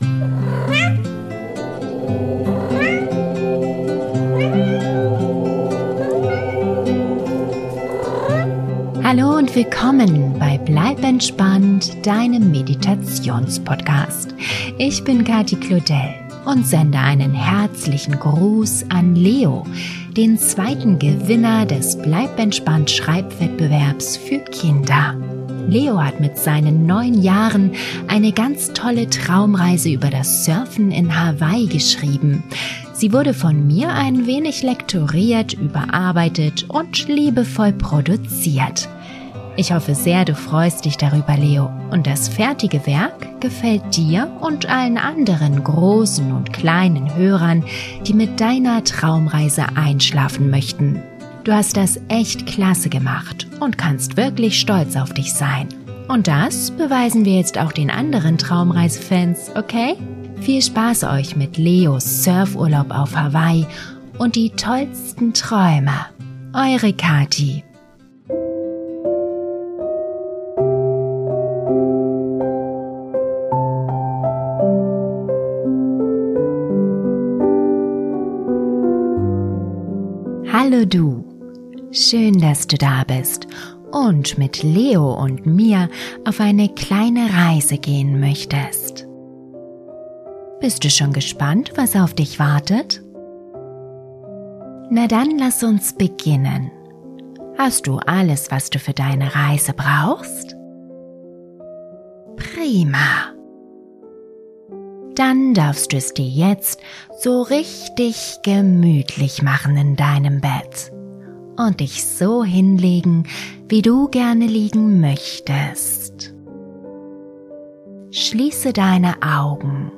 Traumreise für Kinder, Jugendliche und Erwachsene - Surfurlaub auf Hawaii - Geschichte übers Surfen